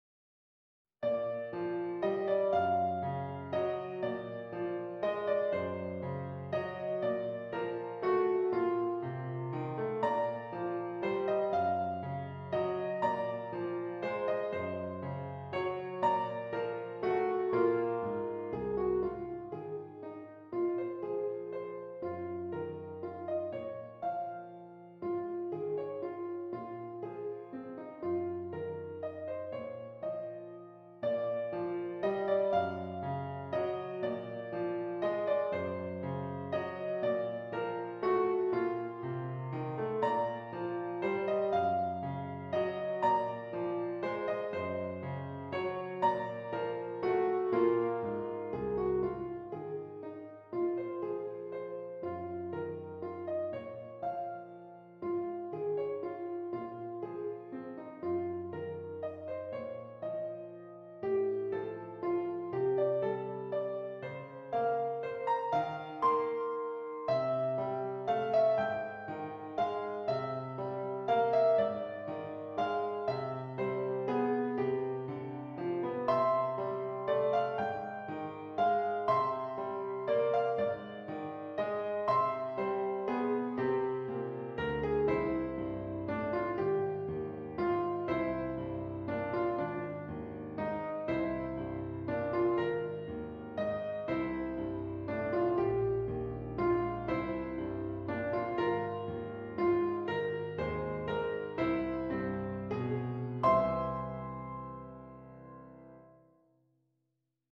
String Quartet
Hi all, I orchestrated my piano work Free and Easy previously posted on the forum for string quartet.